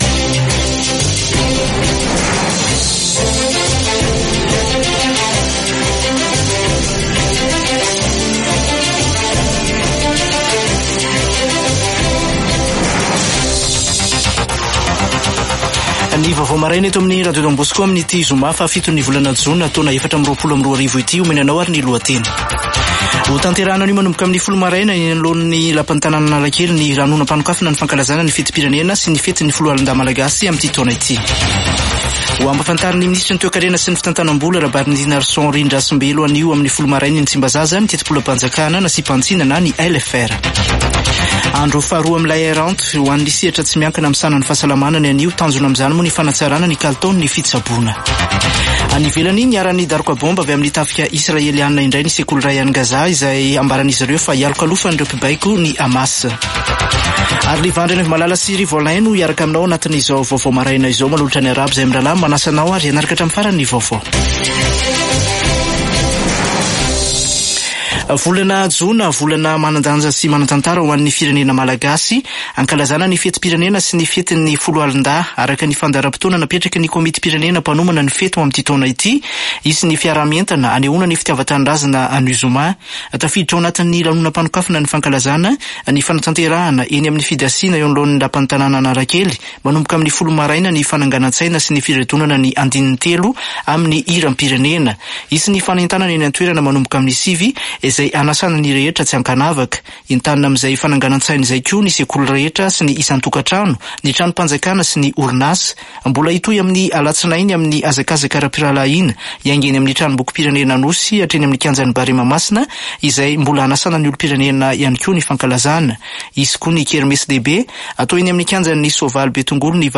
[Vaovao maraina] Zoma 7 jona 2024